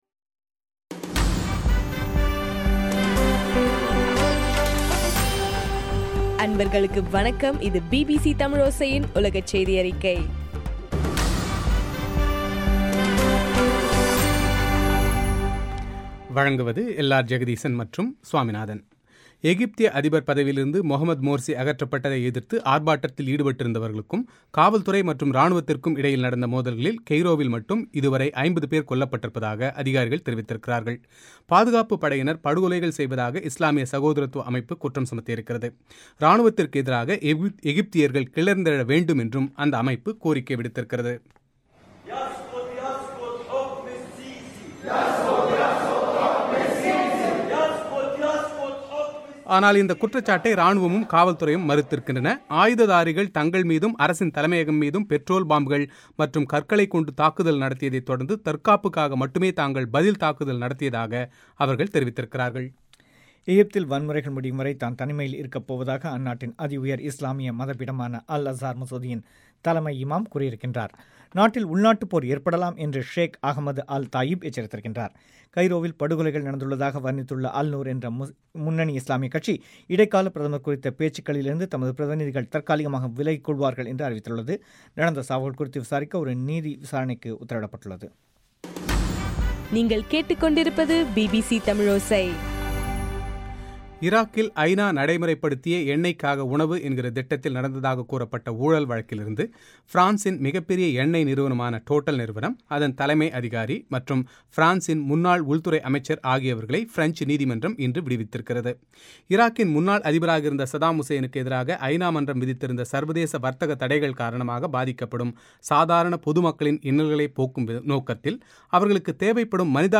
இலங்கையின் சக்தி எஃப் எம் பண்பலையில் ஒலிபரப்பான பிபிசி தமிழின் சர்வதேச செய்தியறிக்கை